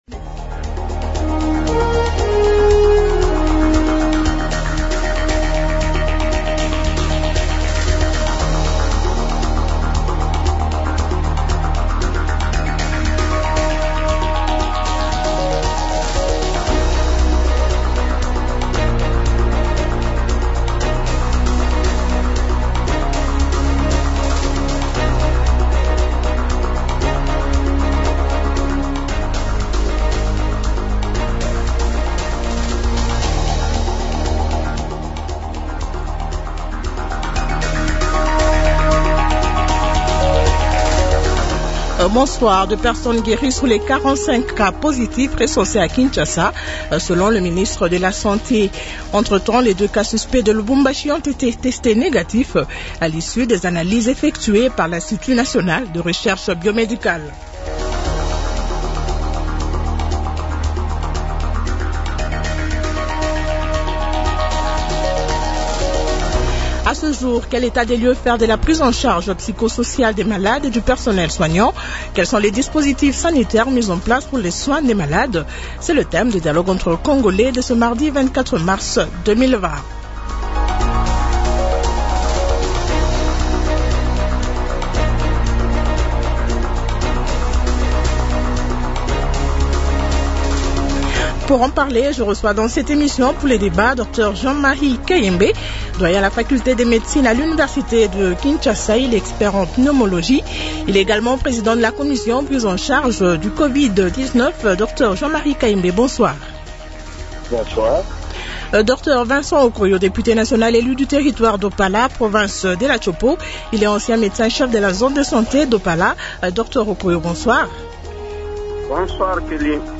-Questions des auditeurs.